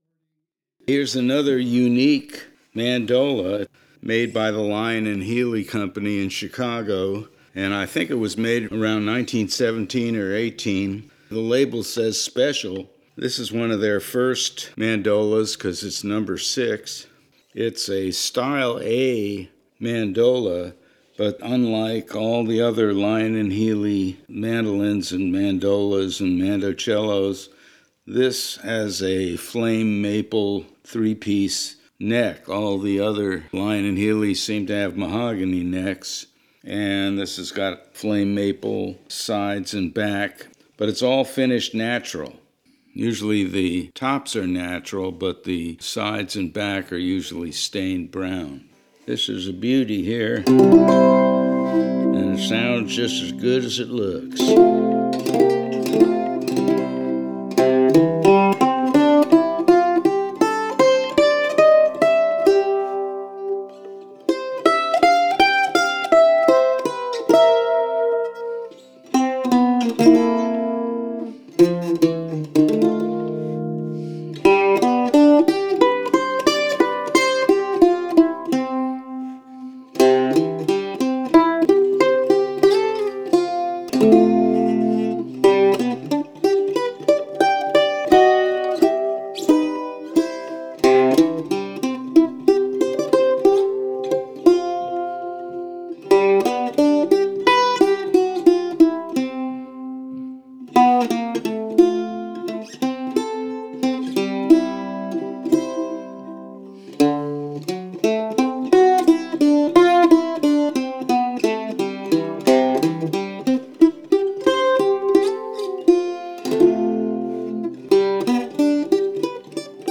1917 Lyon & Healy Style A “Special” Mandola